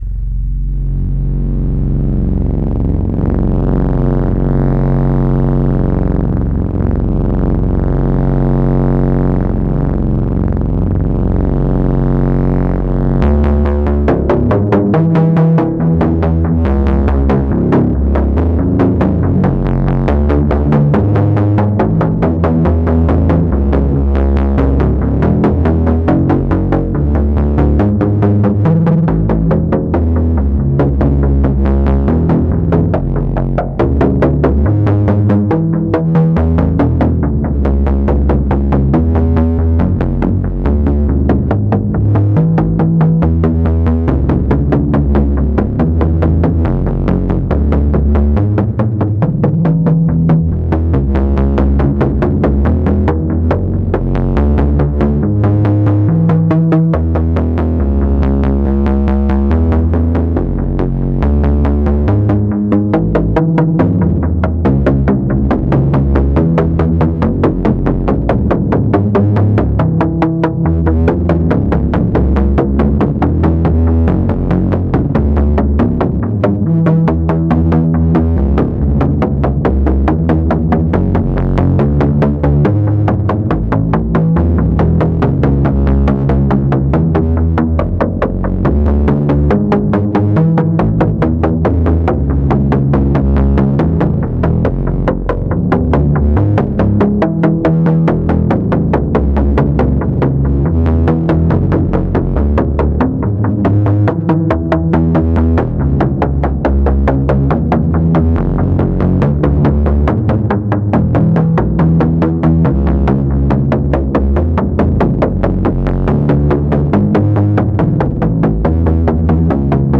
Malevolent VCOs
Doepfer SSI filter
Modulation and sequence from ALM clocked modulation source, Amnis shift register, and pittsburgh function generator.
Smashed into Dannysound compressor/eq/exciter.